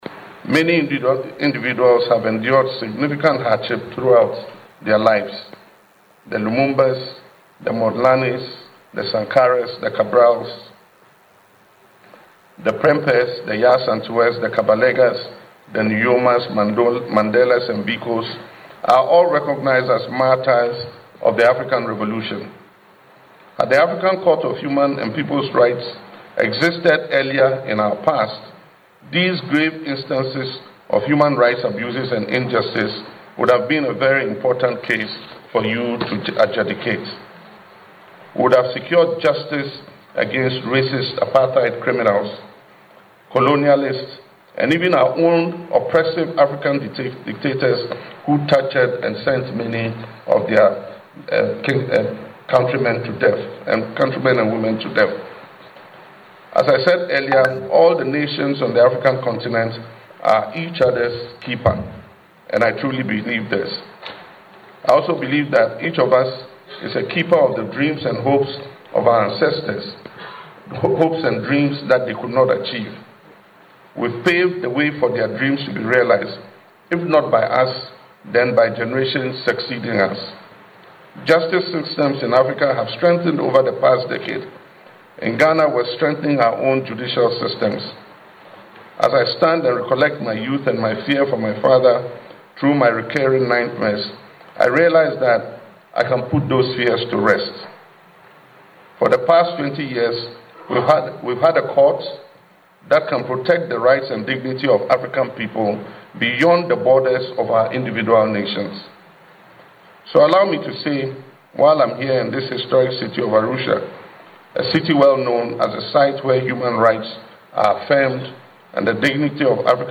Delivering the keynote address at the official opening of the 2026 Judicial Year and the Court’s 20th Anniversary in Arusha, President Mahama stressed that Africa needs an independent judicial institution “that serves all but is beholden to none.”
LISTEN TO PRESIDENT MAHAMA IN THE AUDIO BELOW: